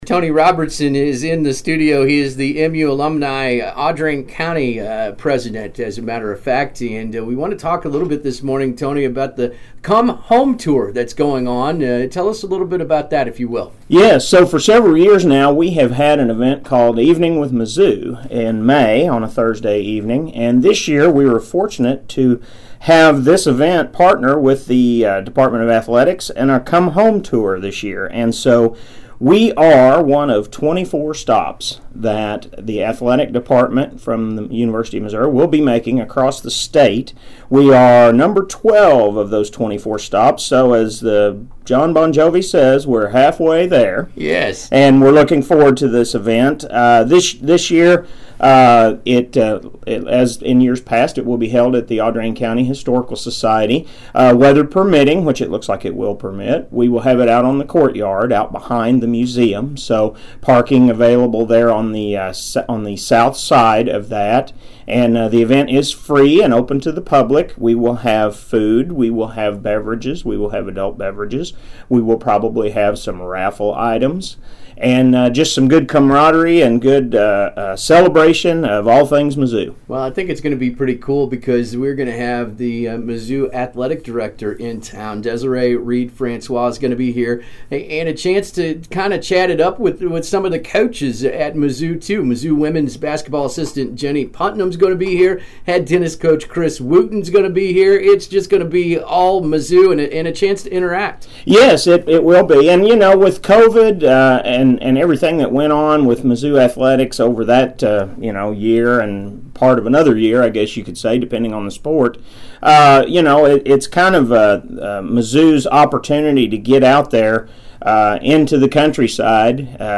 was a featured local guest on AM 1340 KXEO and the Mix Country 96 morning shows today